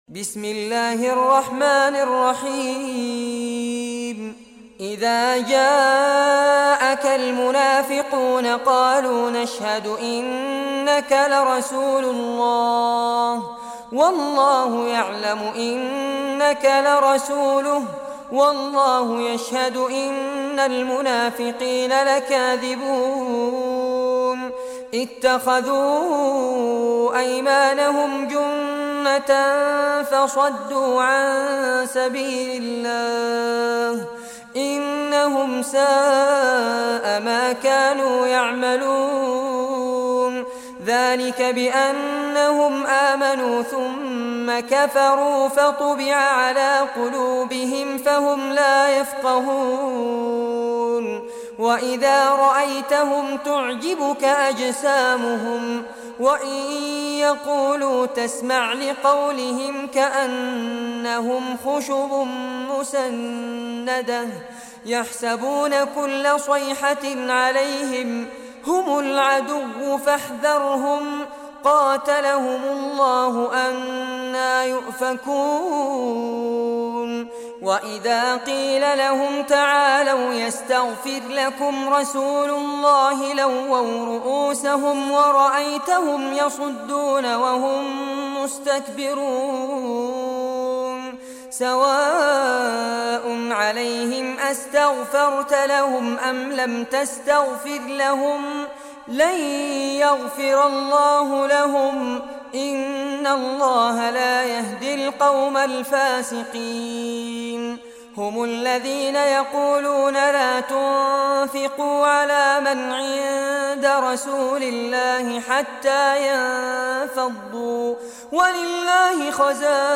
Surah Al-Munafiqun Recitation by Fares Abbad
Surah Al-Munafiqun, listen or play online mp3 tilawat / recitation in Arabic in the beautiful voice of Sheikh Fares Abbad.